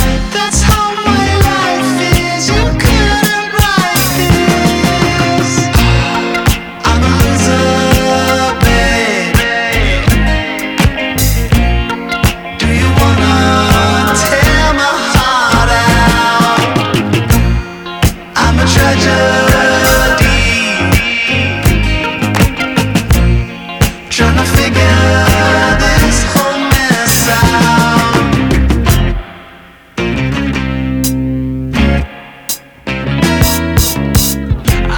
Жанр: Танцевальные / Альтернатива
Alternative, Dance